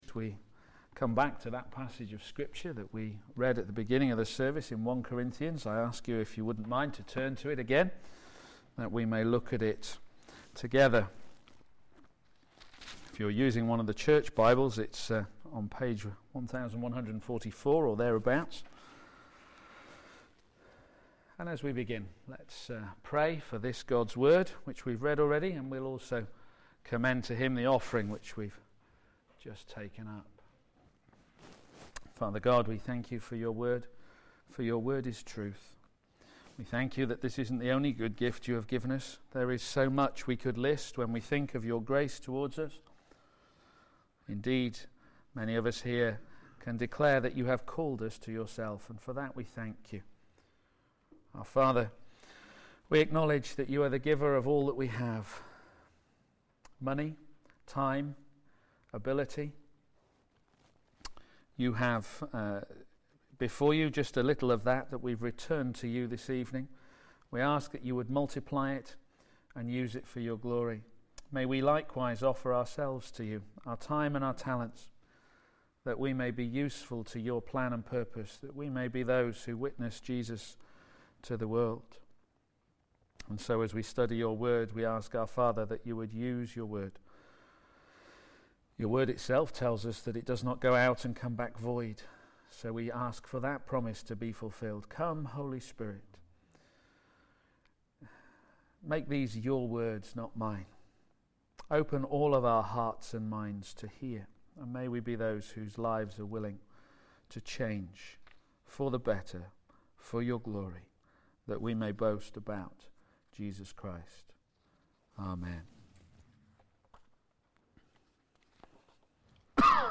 Media for p.m. Service on Sun 16th Jun 2013 18:30
Theme: The message of the cross: Wisdom from God Sermon